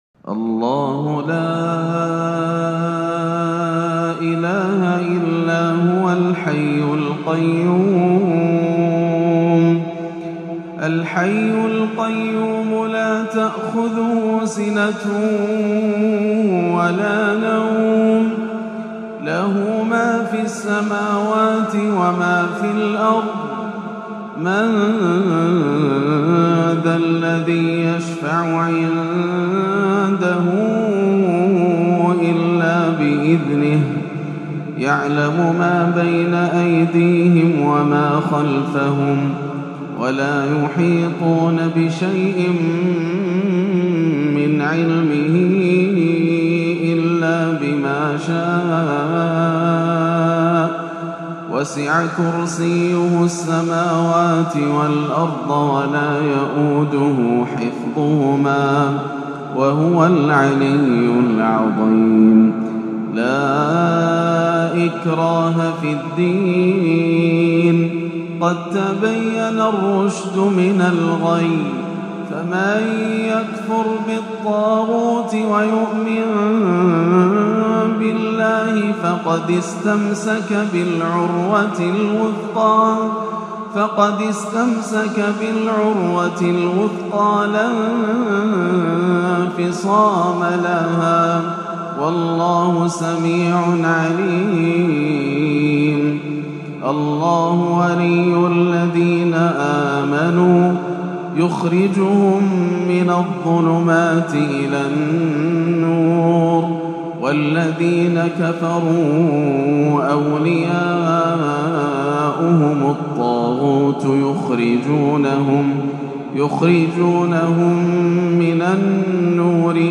مع نسائم الفجر تلاوة حجازية تدبرية "لآيه الكرسي" - السبت 24-11 > عام 1437 > الفروض - تلاوات ياسر الدوسري